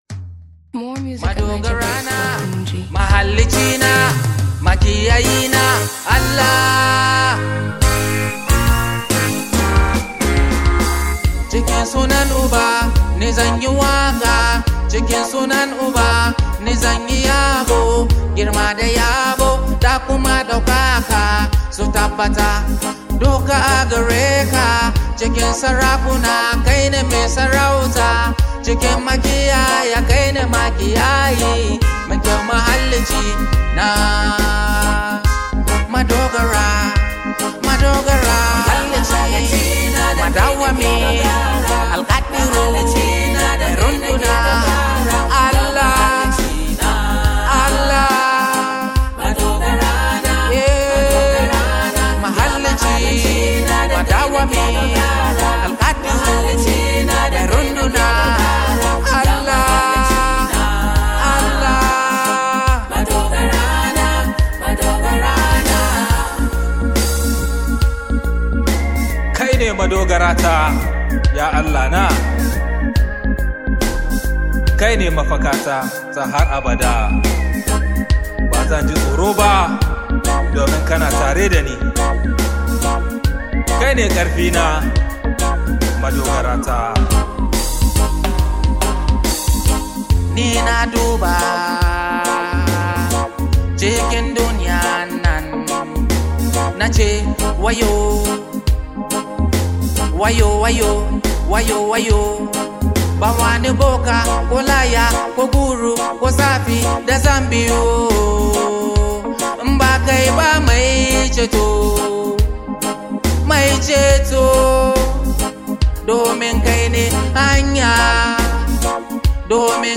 is a soulful Hausa song